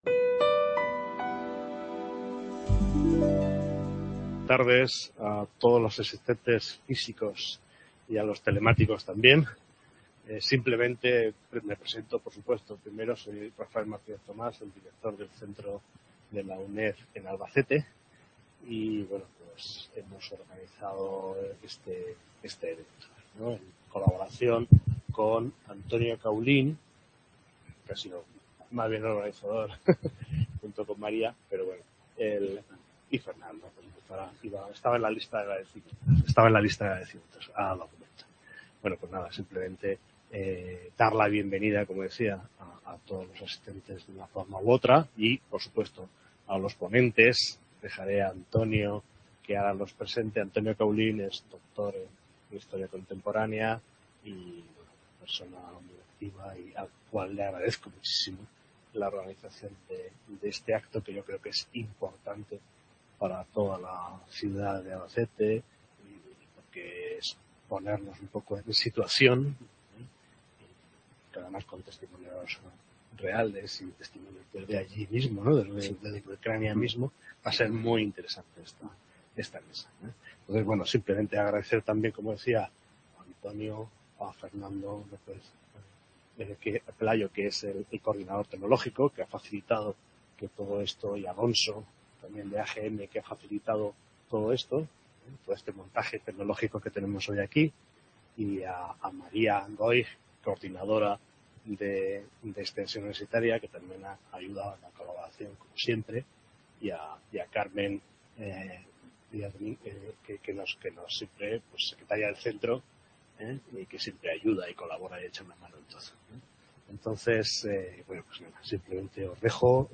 MESA REDONDA
El toque de realismo vendrá dado por una serie de invitados de excepción, dada su nacionalidad ucraniana y cercanía al conflicto, que complementarán con sus testimonios y vivencias, las exposiciones más técnicas.